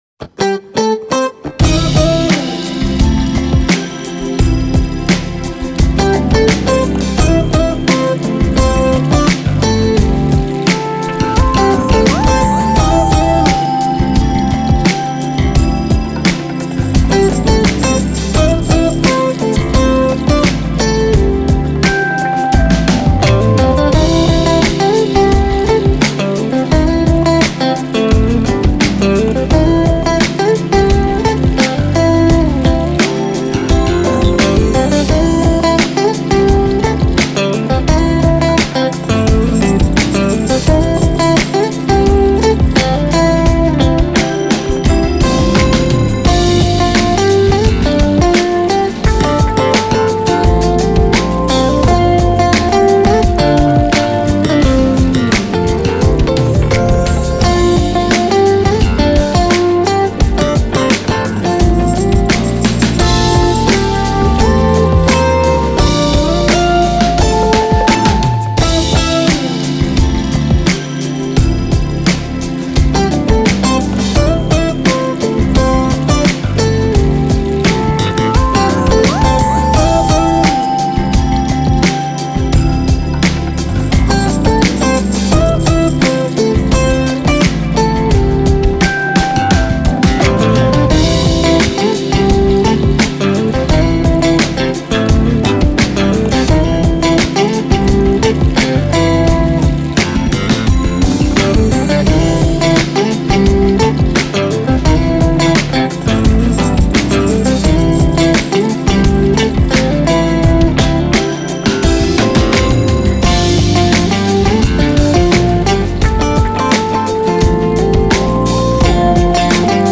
Written for Synth 1&2 and full rhythm section.